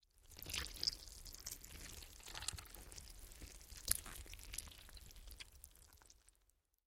На этой странице собраны мрачные и реалистичные звуки расчленения и вскрытия тела. Коллекция включает аудиоэффекты для создания атмосферы хоррора: хруст костей, скрип скальпеля, всплески жидкостей.
Звук слизи расчлененного мертвого тела